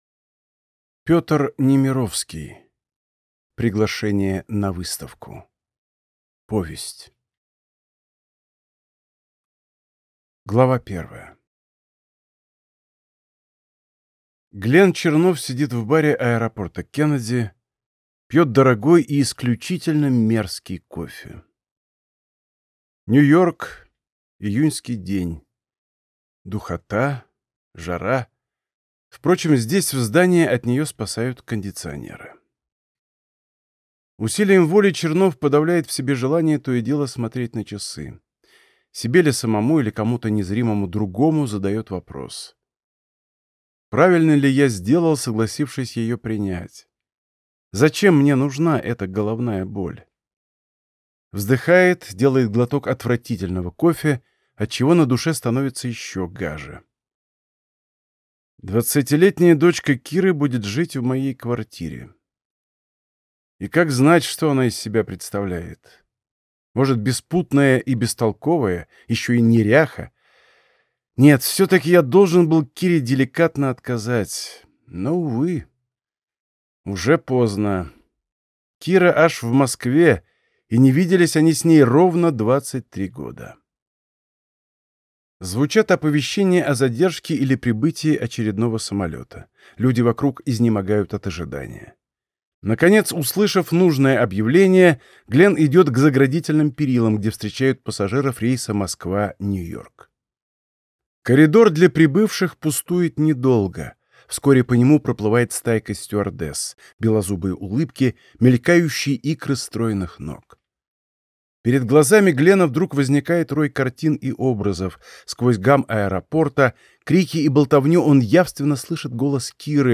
Аудиокнига Приглашение на выставку | Библиотека аудиокниг